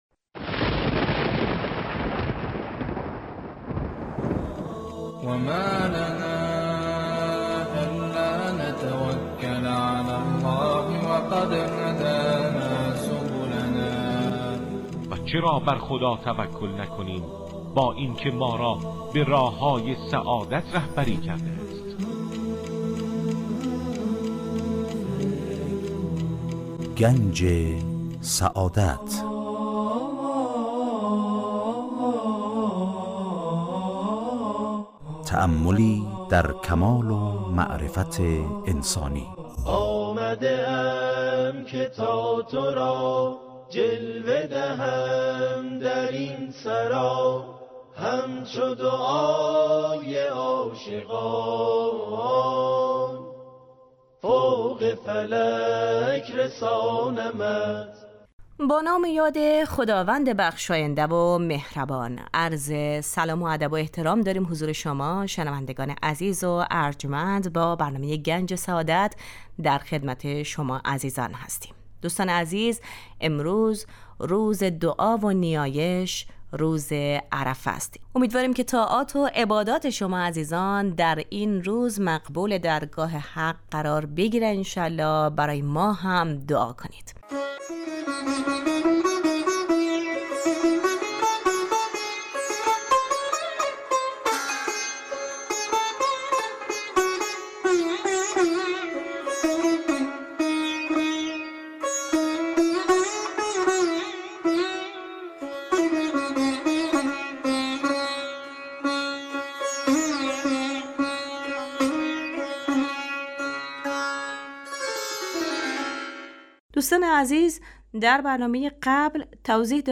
گوینده : سرکار خانم